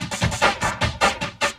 45 LOOP 08-L.wav